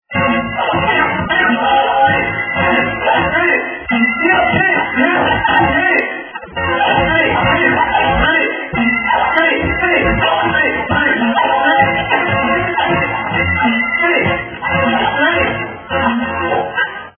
Scratch de Voz c/ Beat
scratchbeatvoz.mp3